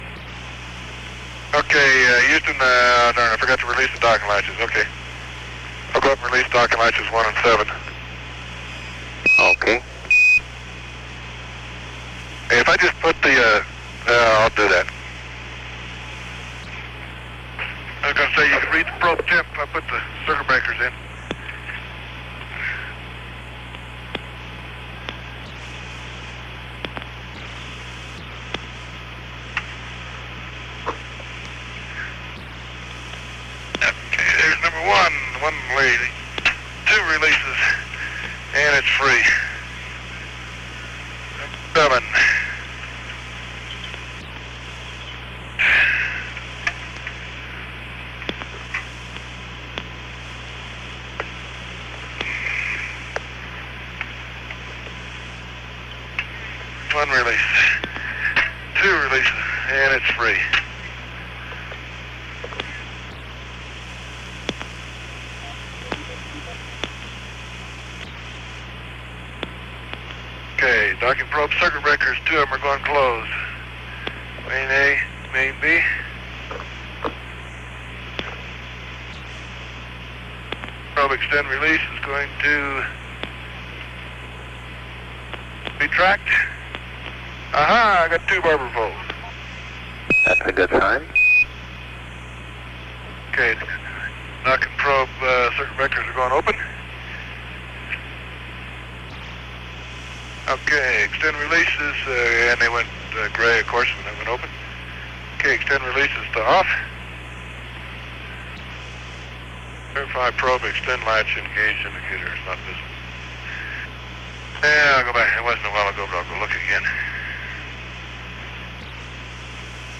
It appears that long quiet periods have been deleted, probably by a process of copying from one tape machine to another.